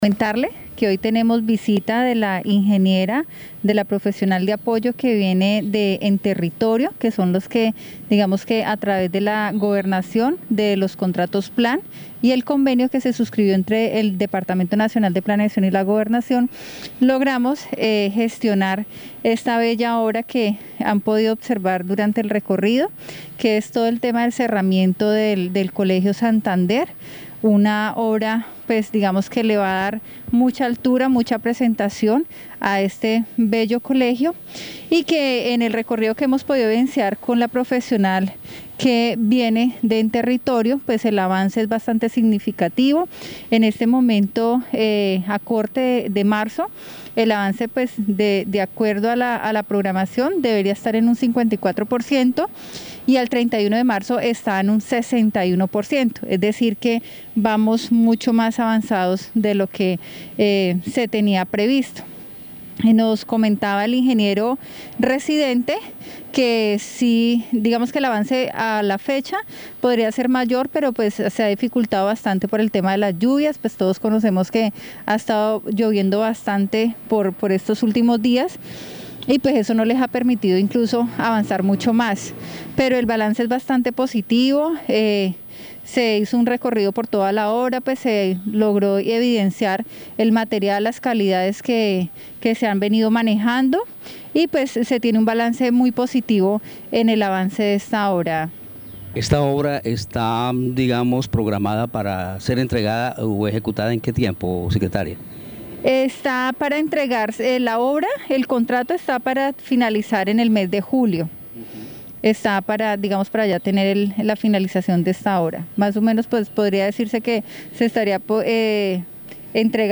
Escuche a Alexandra Rayo, secretaria de Educación del Guaviare.